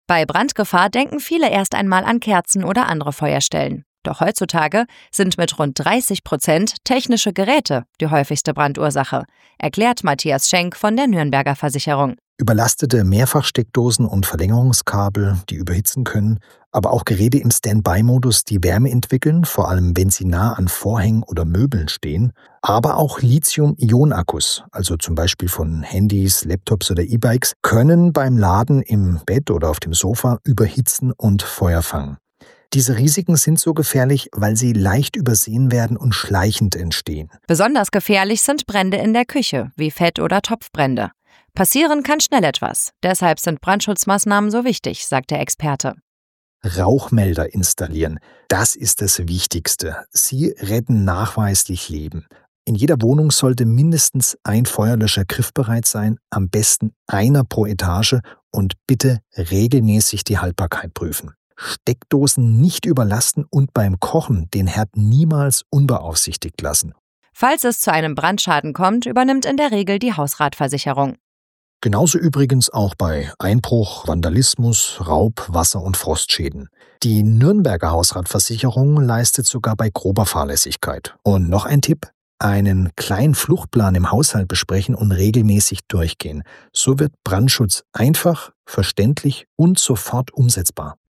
nuernberger-beitrag-brandschutztag-radionews.mp3